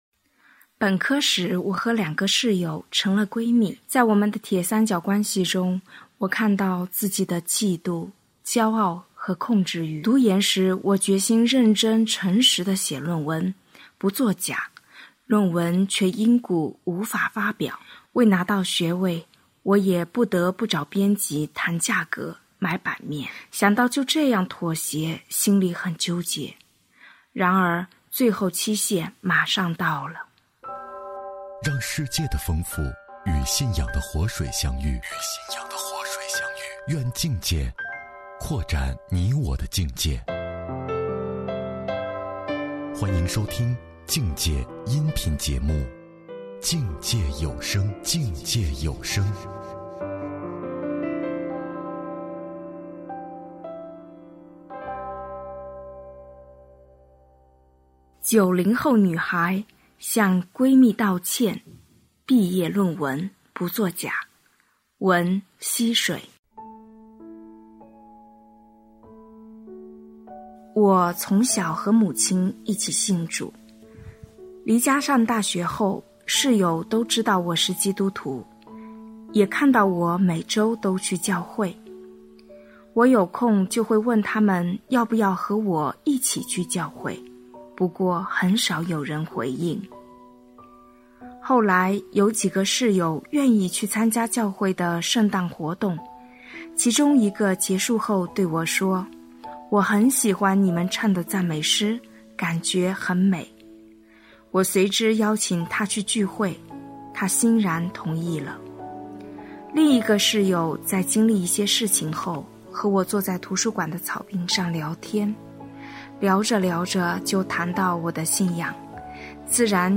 《境界》独立出品【这世代·90后】 文丨Minnie 播音